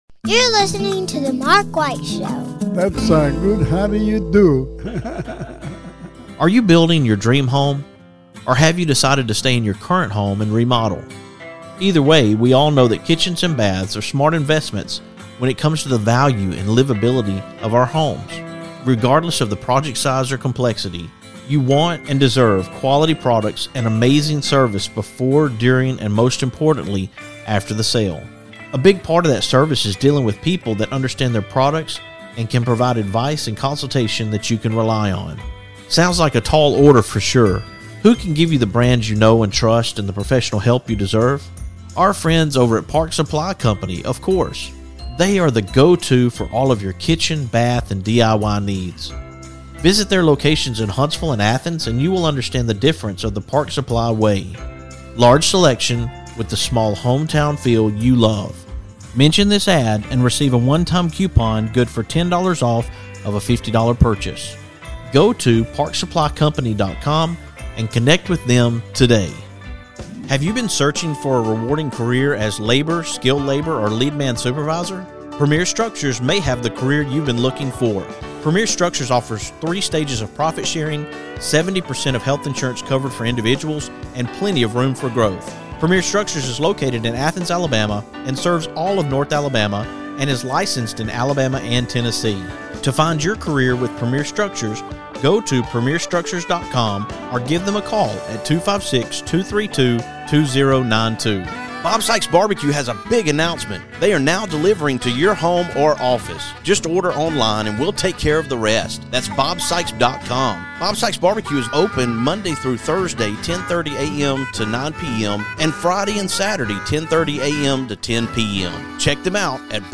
This morning, I was at Tanner High School as the Tanner High School Boys Soccer team received their championship rings.